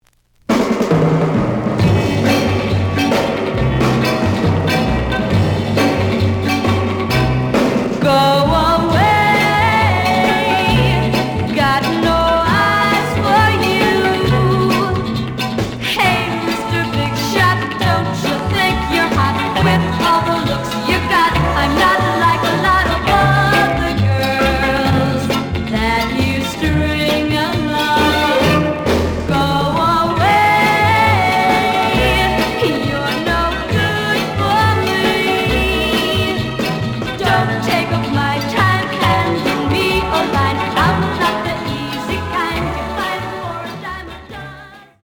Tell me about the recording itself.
The audio sample is recorded from the actual item. B side plays good.)